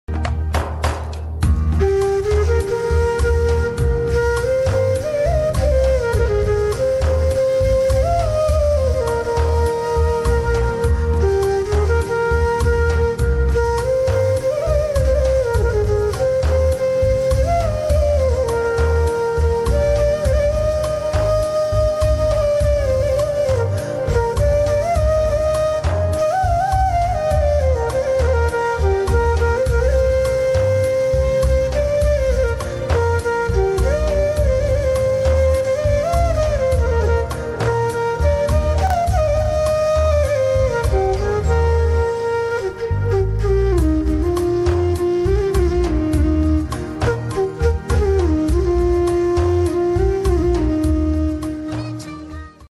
Different scail flutes ready for